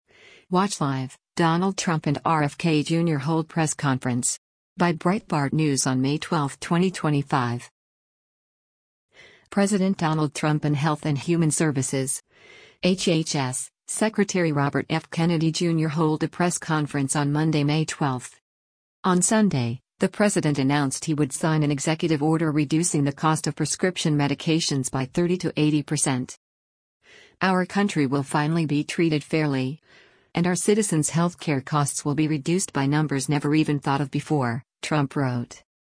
President Donald Trump and Health and Human Services (HHS) Secretary Robert F. Kennedy Jr. hold a press conference on Monday, May 12.